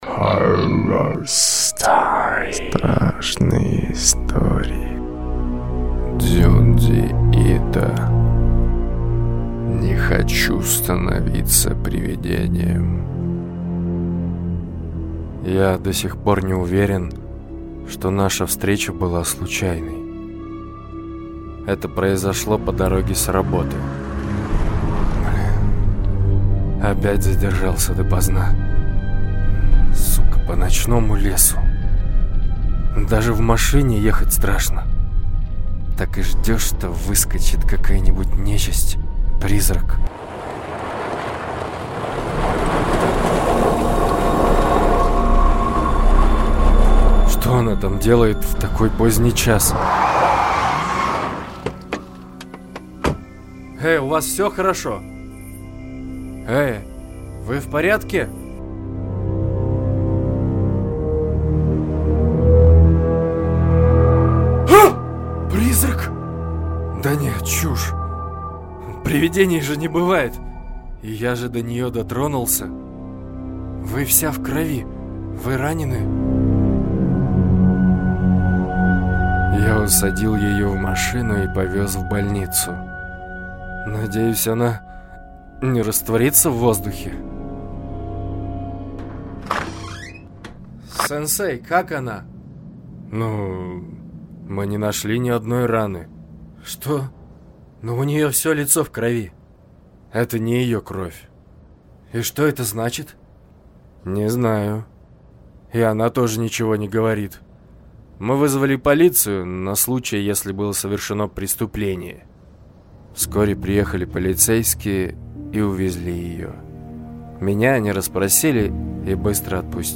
СТРАШНЫЕ ИСТОРИИ в формате АУДИОКИНО. Актерское прочтение по ролям, атмосферная фоновая музыка и окружающие звуки погрузят вас в эти истории.